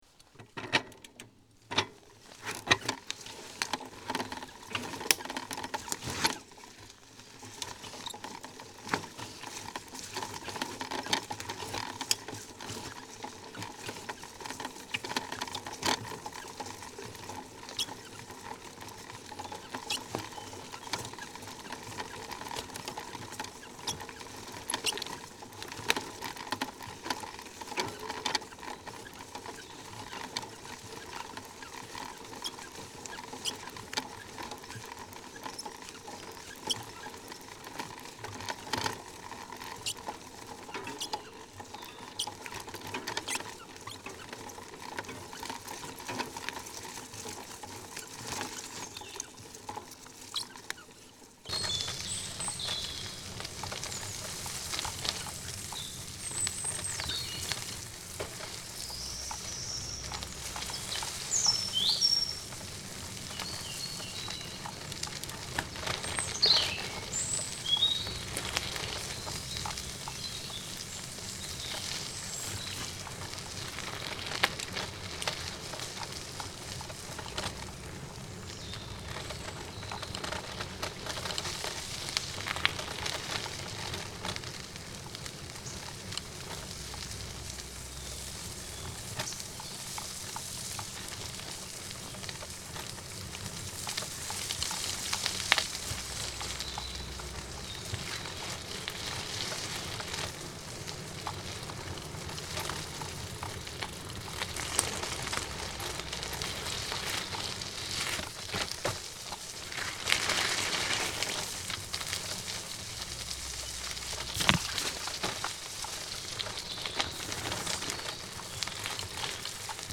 Разные велосипедные звуки: колеса и трещотки велосипедные, езда на велосипеде, звонок, тормоза, цепи.
2. Атмосферный звук езды на велосипеде в лесу
ezda-na-velo-v-lesu.mp3